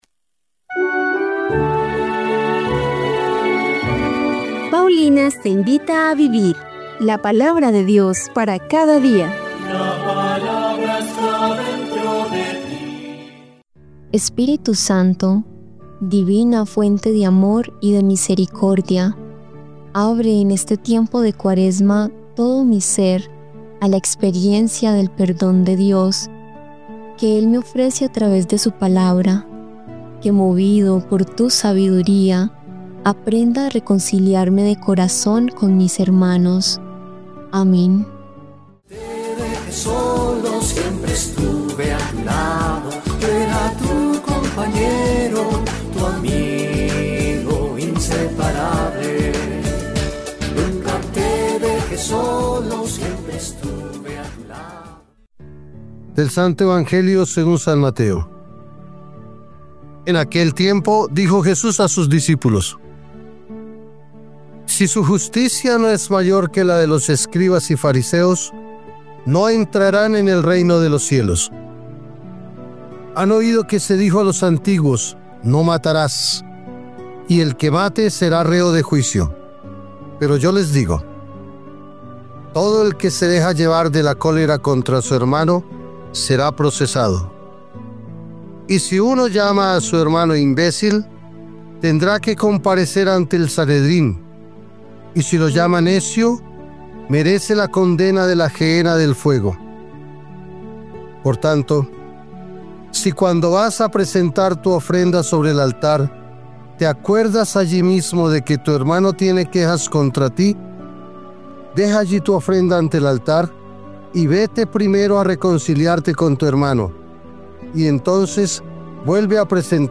Lectura del libro del Éxodo 32, 7-14